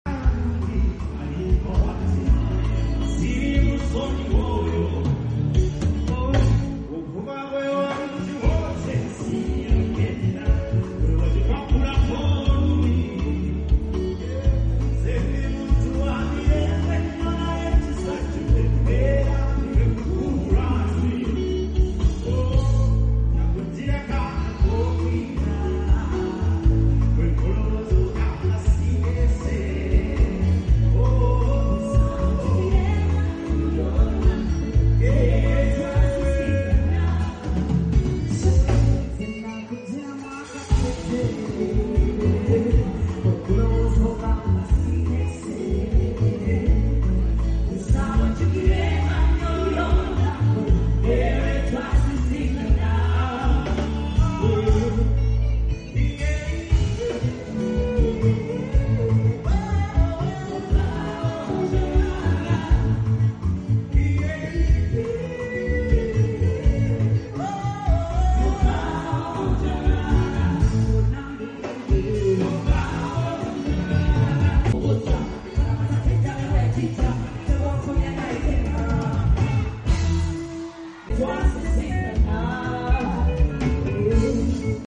Great performance